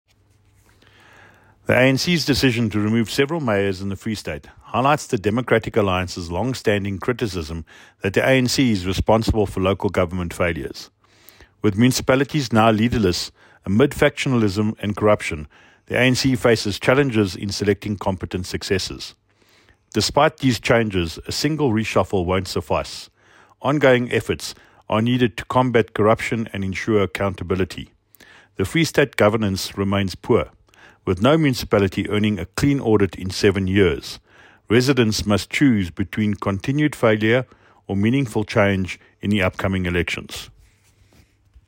Afrikaans soundbites by David Mc Kay MPL and